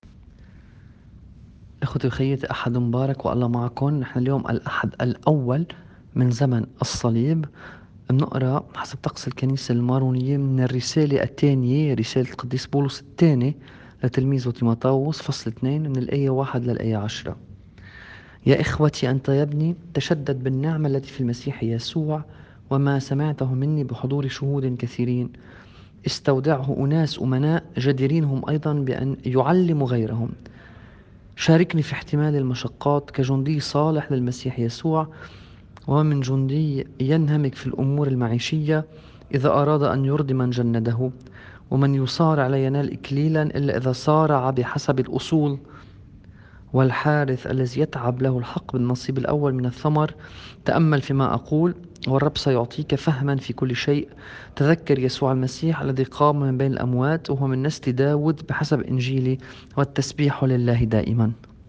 الرسالة بحسب التقويم الماروني :